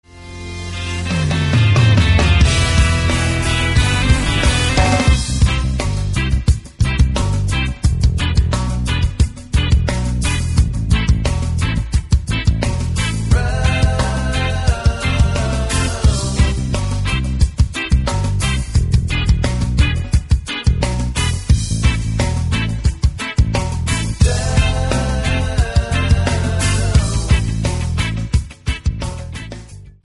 MP3 – Original Key – Backing Vocals Like Original